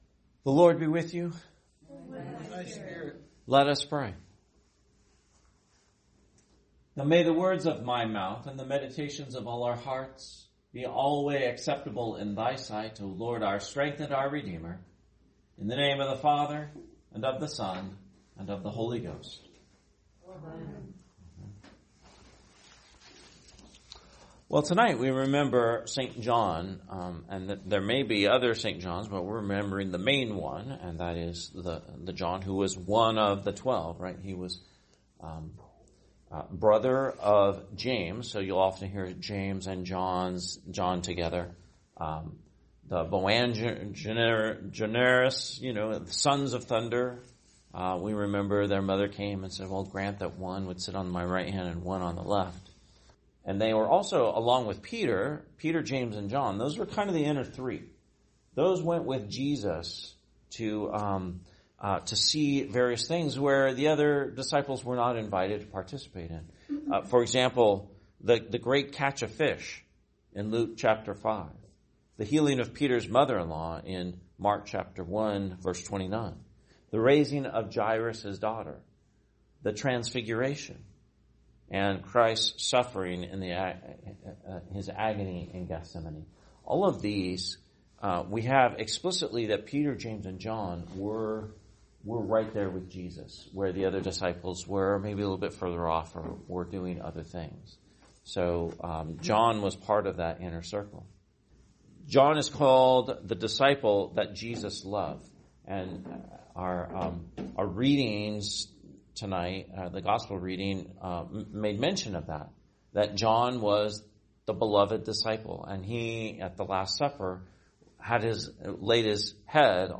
Homily, Feast of St. John, 2025